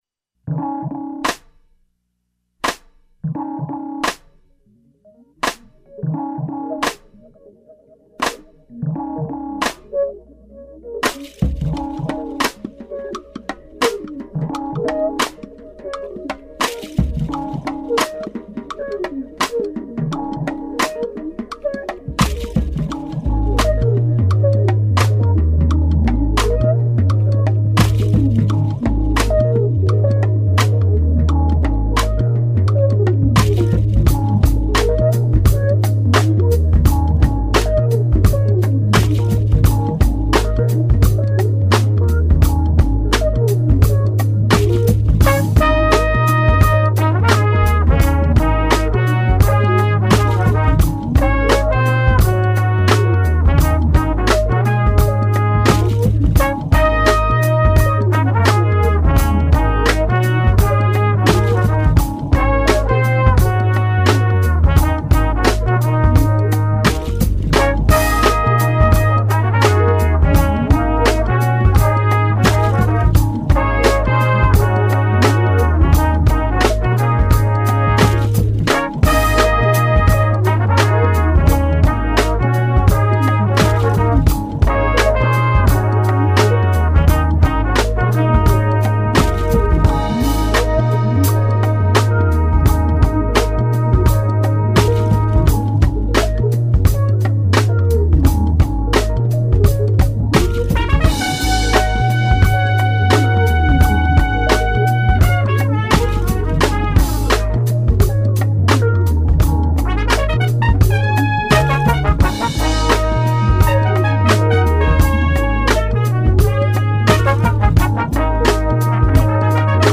An episode by Interviews and Live Sessions